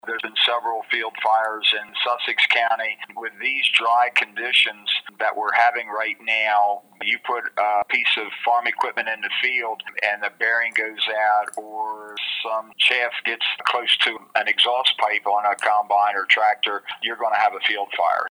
In agricultural areas in northern Kent County and Southern New Castle County as well as in Sussex County, field fires have been occurring as a result of the prolonged dry conditions. As Delaware Secretary of Agriculture Michael Scuse tells the Talk of Delmarva–it’s been very difficult for the fire companies and the producers…